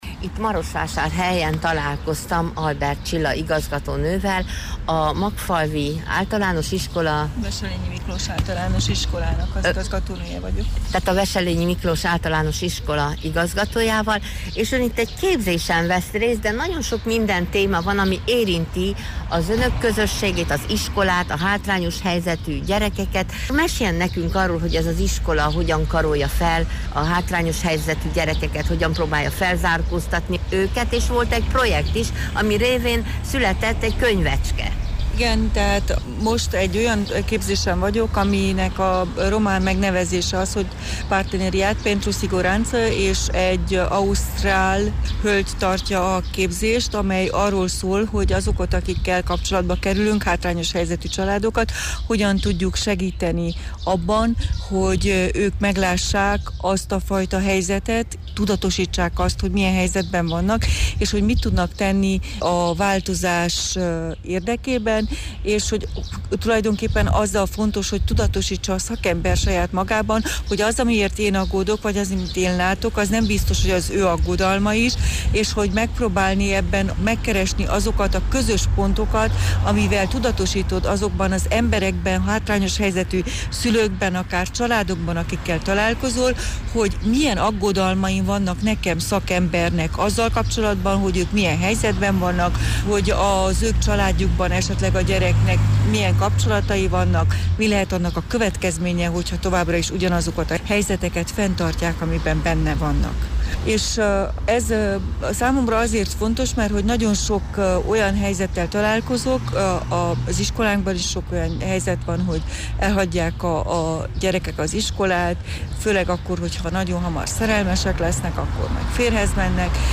A Vársétányon találkoztunk, ott egy padon beszélgettünk el, szívhez szóló beszélgetés volt, amelyből kiderült, hogy számára minden gyermek, aki az iskola kapuját átlépi, egyformán kedves, legyen az magyar, román, roma, a sorrendet meg is fordíthatjuk.